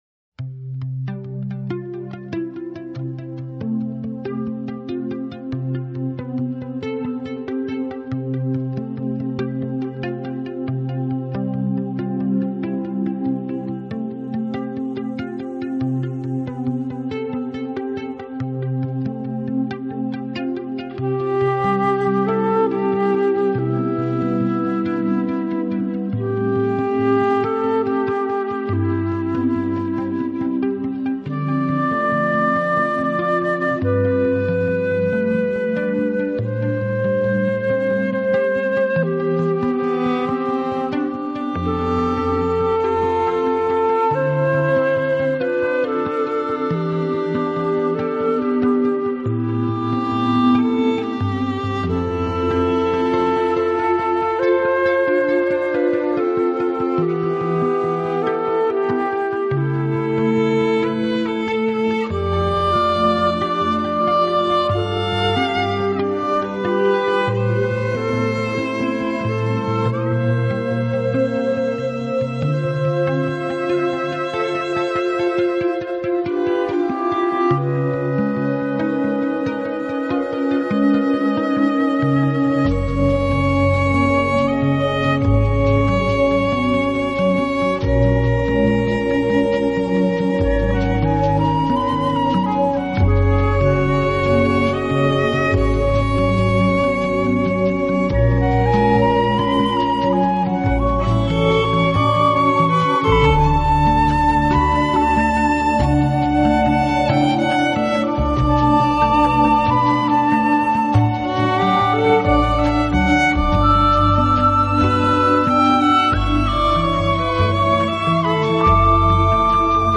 音乐类型：New Age